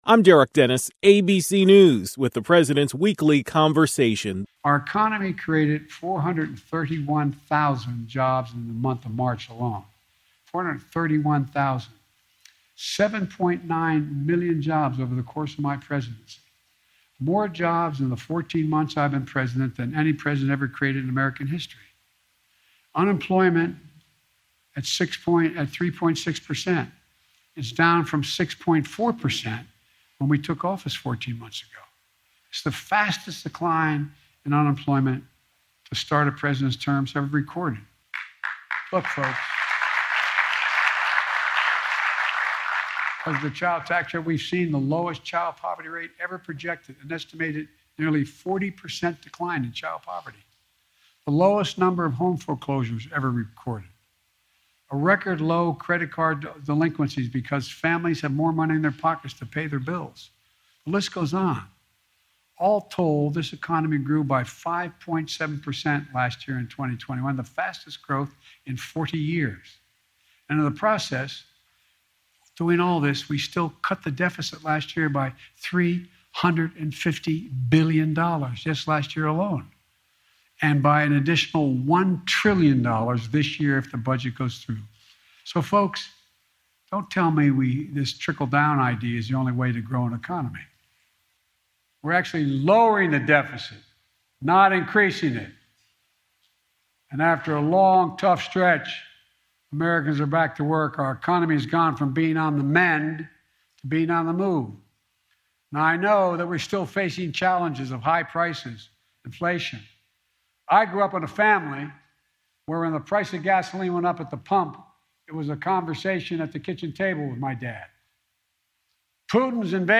President Biden discussed the status of the economy.